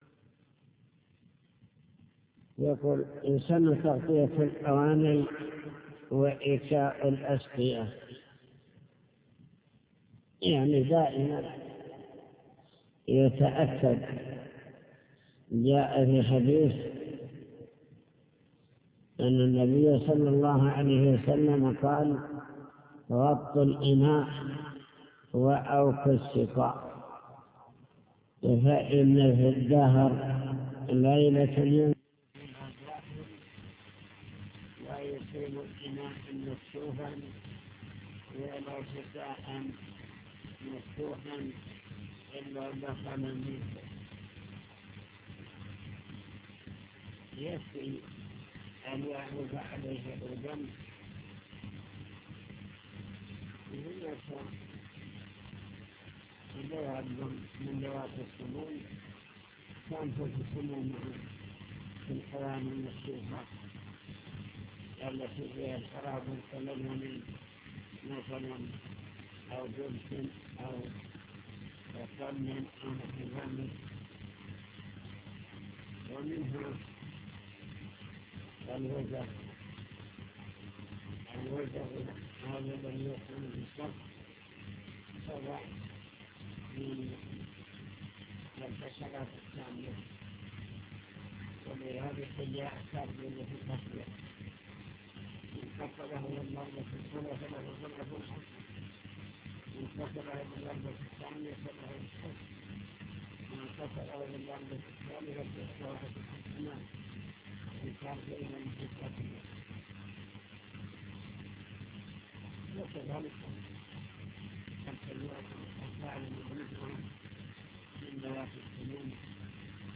المكتبة الصوتية  تسجيلات - كتب  شرح كتاب دليل الطالب لنيل المطالب كتاب الطهارة باب الآنية